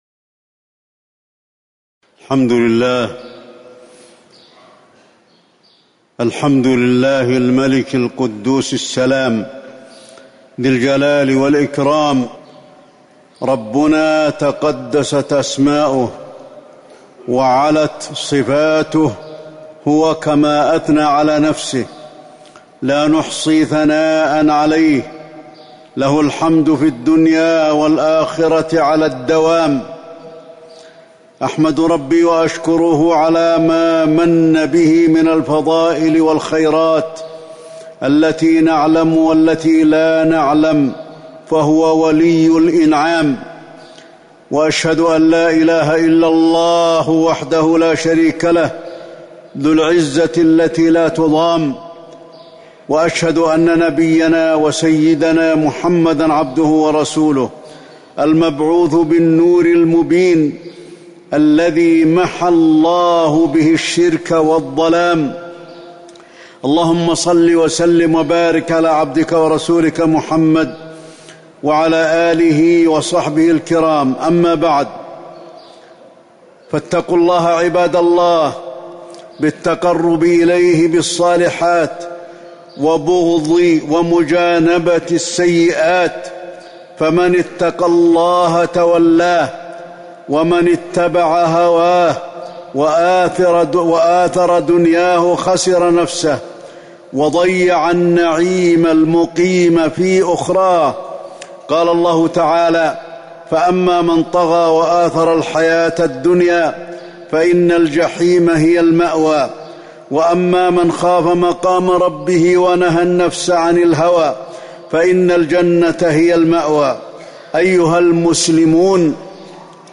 تاريخ النشر ٤ ربيع الأول ١٤٤١ هـ المكان: المسجد النبوي الشيخ: فضيلة الشيخ د. علي بن عبدالرحمن الحذيفي فضيلة الشيخ د. علي بن عبدالرحمن الحذيفي فضل ذكر الله